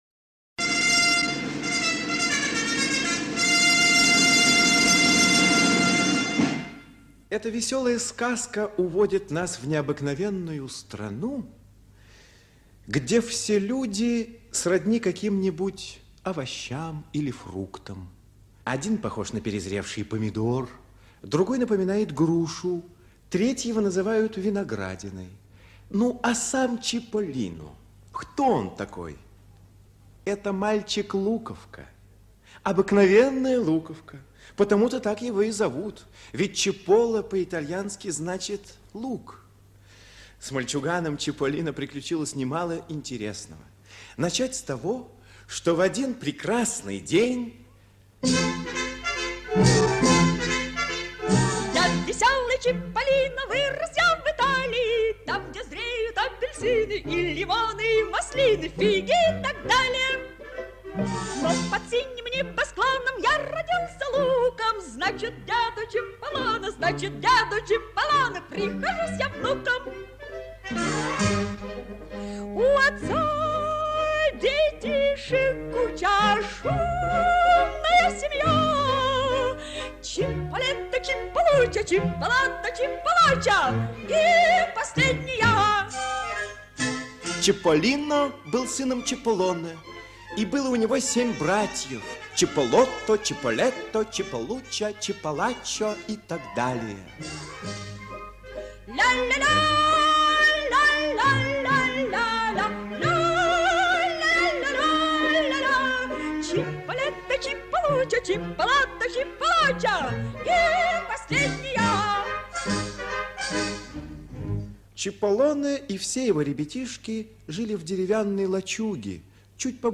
Приключения Чиполлино аудио спектакль 1951г
priklyucheniya-chipollino-gramplastinka.mp3